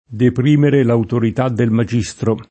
depr&mere l autorit# ddel maJ&Stro] (Ariosto) — sim. i cogn. Magistri, Magistro